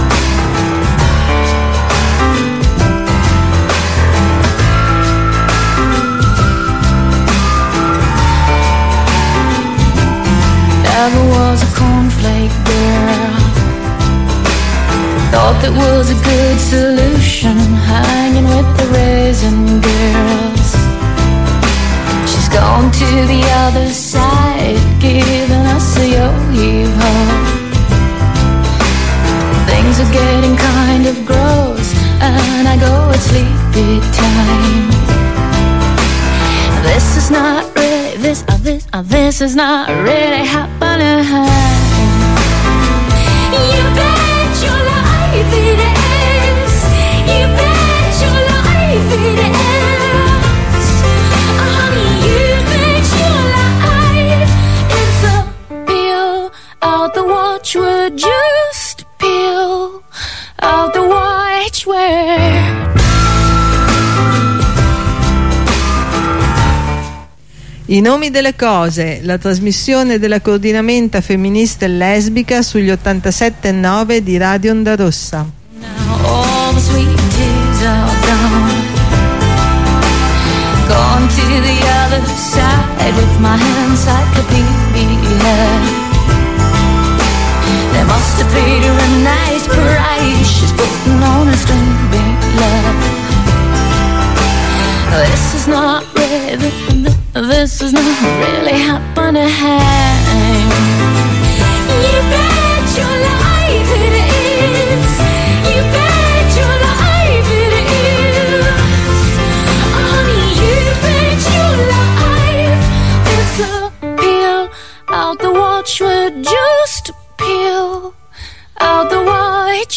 primo collegamento introduttivo al corteo